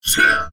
文件 文件历史 文件用途 全域文件用途 Enjo_atk_03_2.ogg （Ogg Vorbis声音文件，长度0.5秒，155 kbps，文件大小：10 KB） 源地址:地下城与勇士游戏语音 文件历史 点击某个日期/时间查看对应时刻的文件。